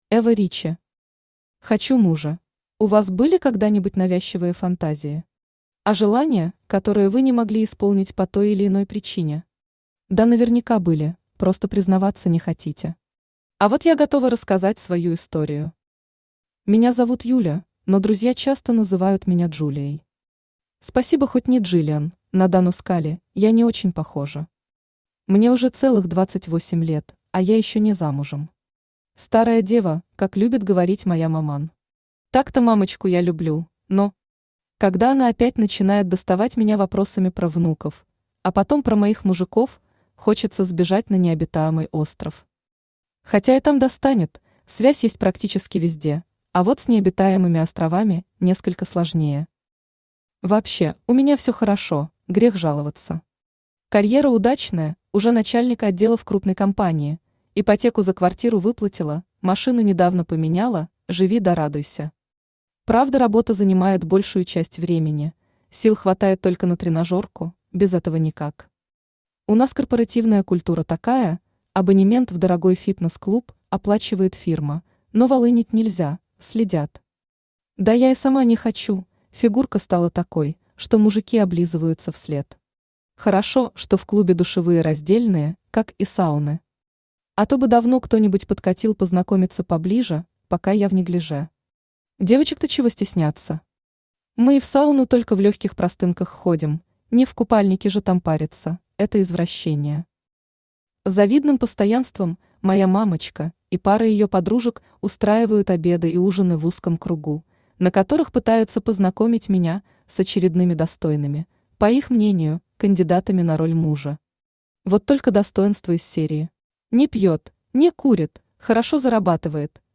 Аудиокнига Хочу мужа | Библиотека аудиокниг
Aудиокнига Хочу мужа Автор Эва Ричи Читает аудиокнигу Авточтец ЛитРес.